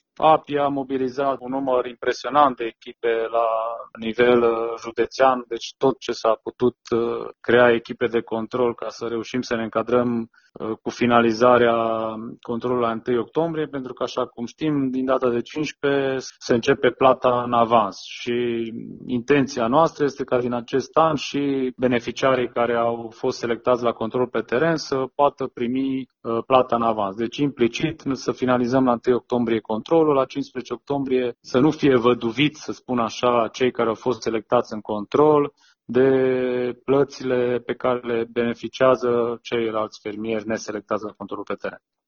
Directorul APIA Mureș, Ovidiu Săvâșcă, a declarat pentru RTM că inspectorii se vor grăbi cu controlul, astfel încât cei verificați să primească subvenția la timp, odată cu cei care nu au intrat în verificări: